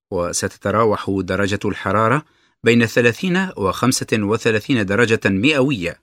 [уа сэтатара̄ух̣у дара́джатуль х̣ара̄ра бэ́йна с̱аляс̱ӣна уа  х̱а́мсатин уа с̱аляс̱ӣна дара́джатан ми`ауӣйя]